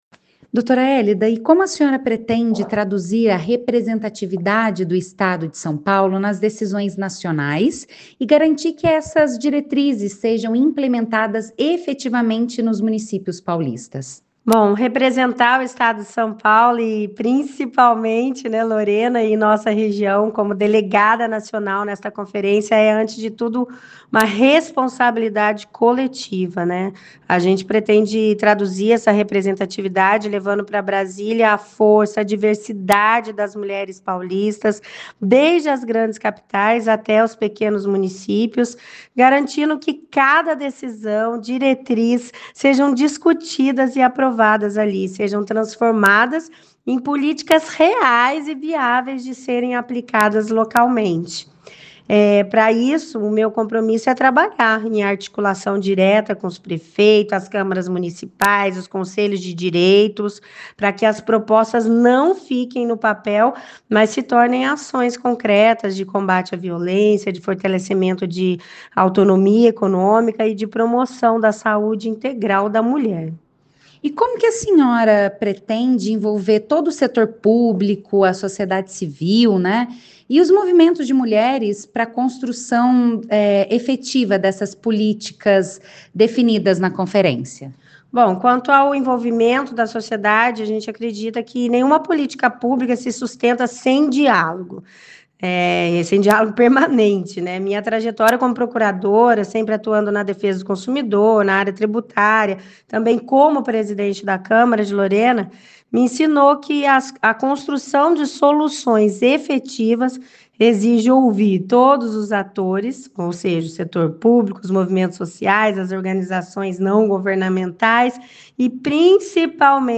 Entrevista (áudios):